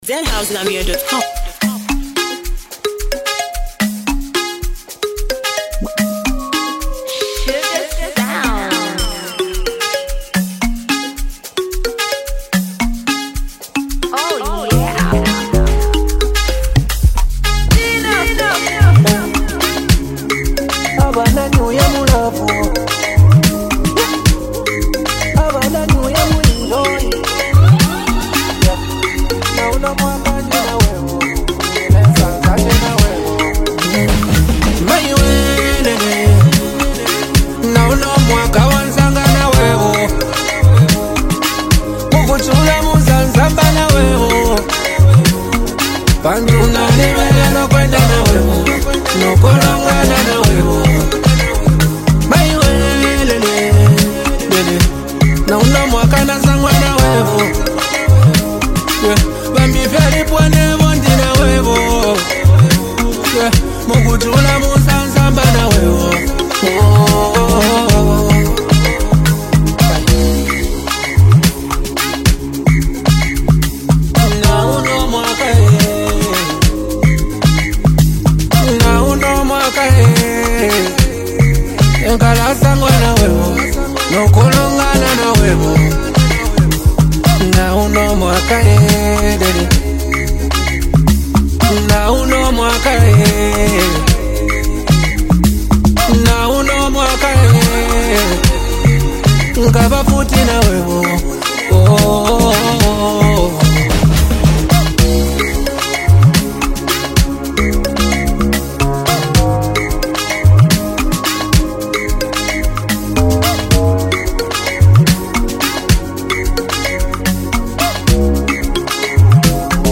With a smooth melody and relatable lyrics